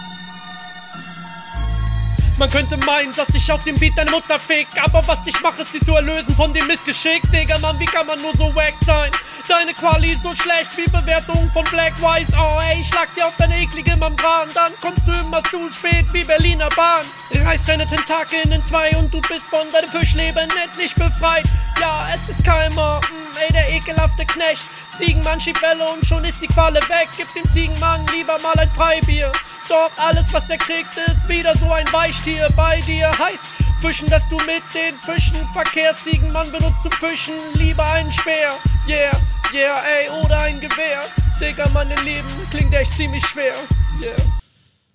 klingt bisschen dünn und wie ein onetake. kann mich nich ganz entscheiden ob das cool …